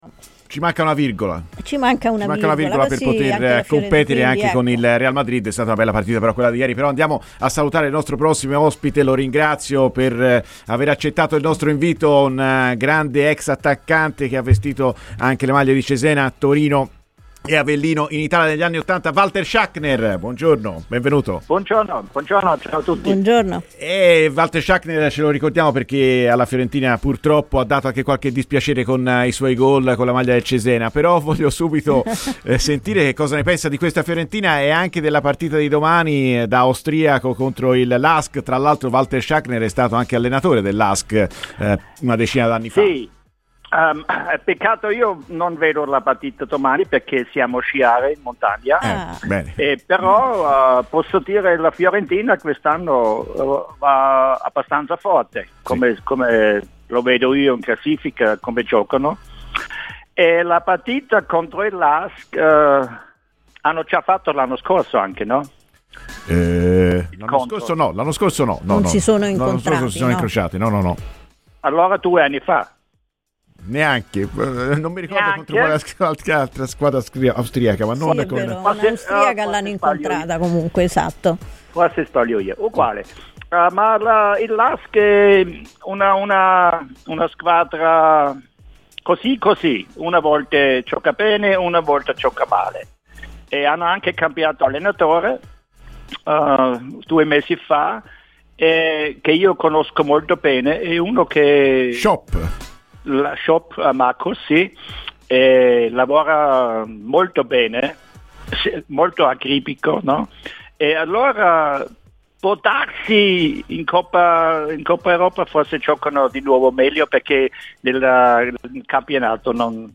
Walter Schachner, ex attaccante austriaco passato dalla Serie A con le maglie tra le altre di Cesena e Torino, ha parlato oggi a Radio Firenzeviola, durante 'Viola amore mio', presentando il Lask prossimo avversario di Conference League di domani: "In passato l'ho anche allenato, il Lask, anche se purtroppo domani non riuscirò a seguire la partita.